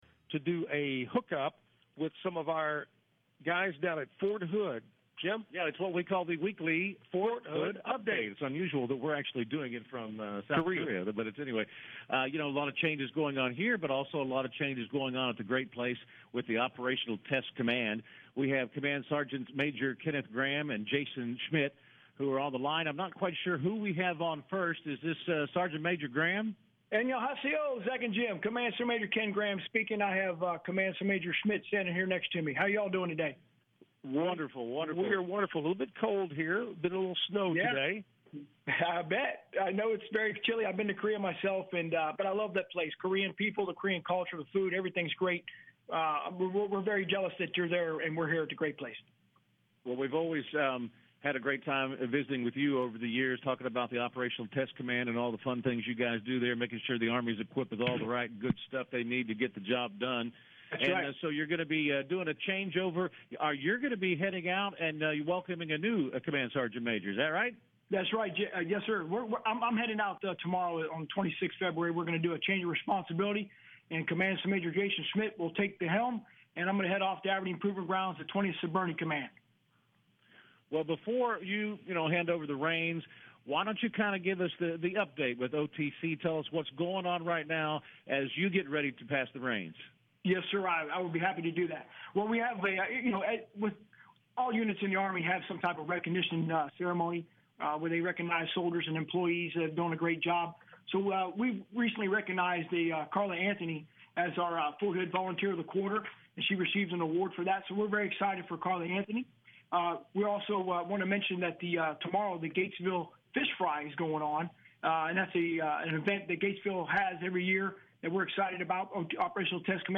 Radio Interview with Command Sgts.